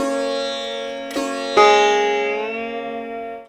SITAR LINE51.wav